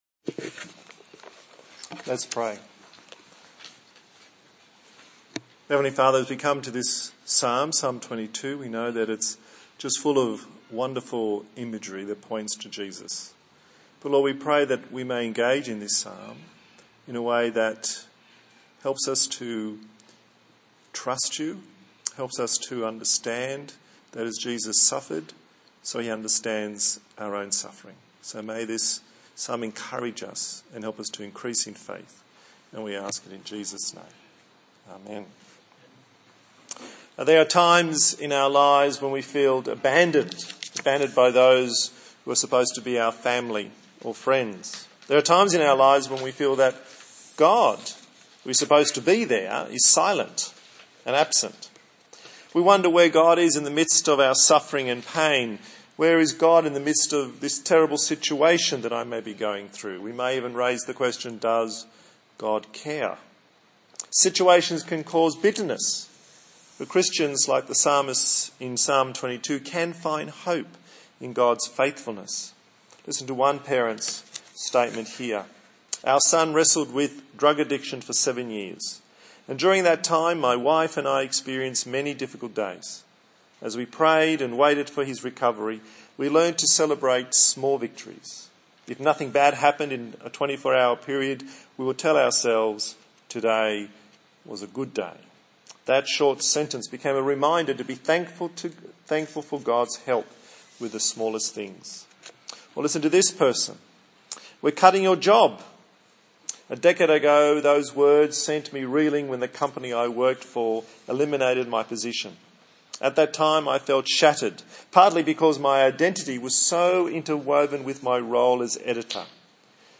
A sermon on the book of Psalms